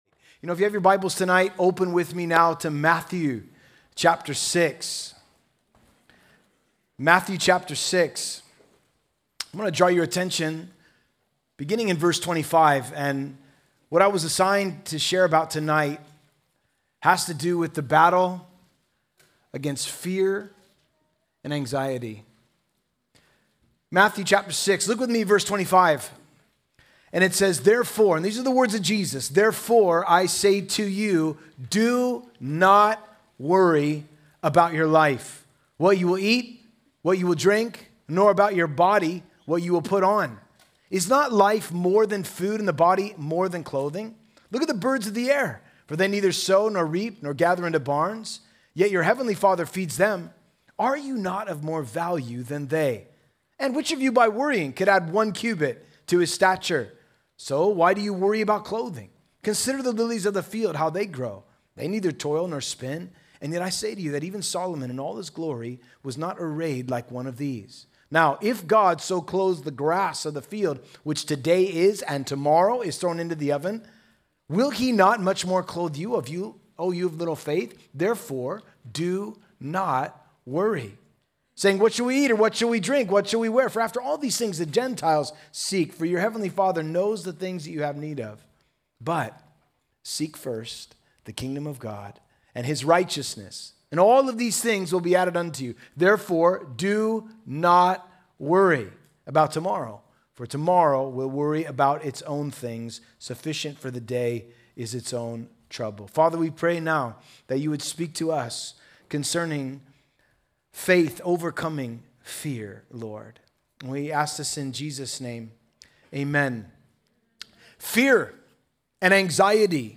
Home » Sermons » The Fight Against Fear
Youth Conference